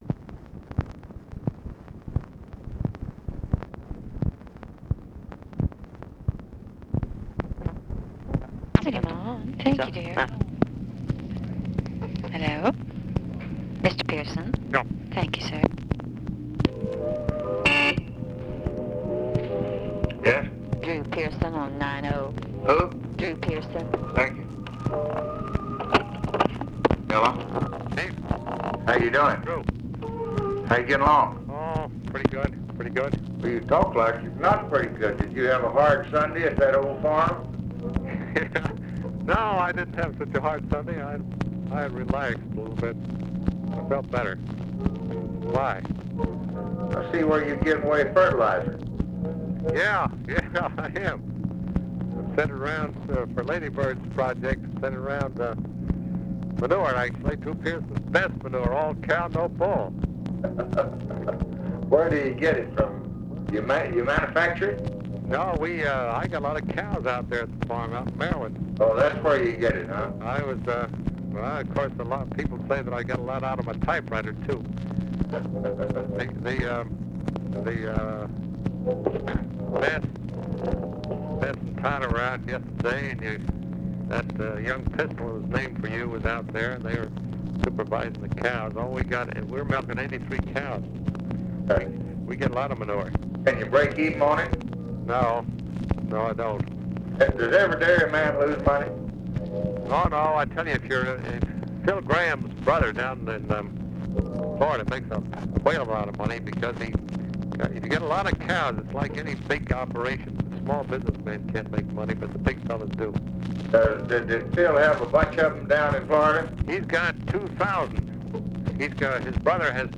Conversation with DREW PEARSON, May 24, 1965
Secret White House Tapes